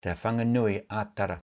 Wellington (Māori: Te Whanganui-a-Tara [tɛ ˈɸaŋanʉi a taɾa] or Pōneke [pɔːnɛkɛ]) is the capital city of New Zealand. It is located at the south-western tip of the North Island, between Cook Strait and the Remutaka Range.